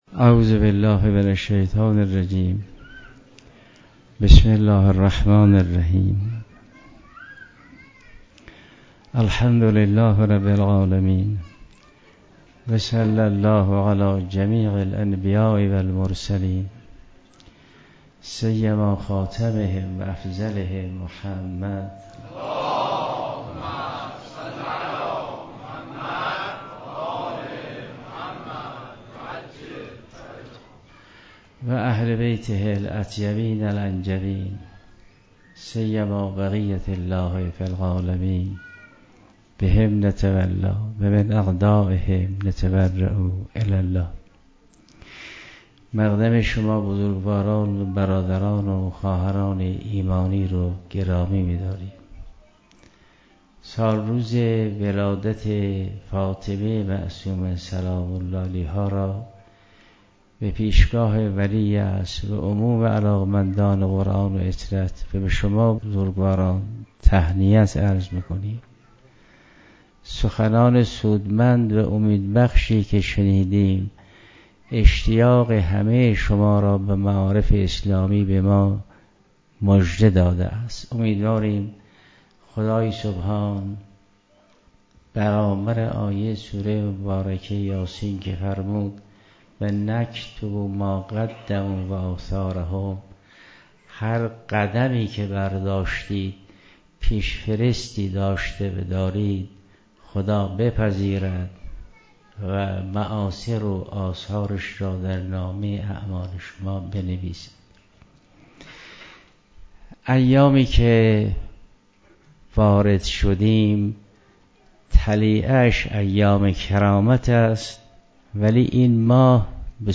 درس اخلاق 14/5/95